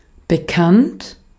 Ääntäminen
IPA: /bəˈkant/